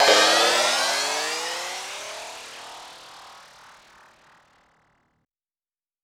Waka TRAP TRANSITIONZ (39).wav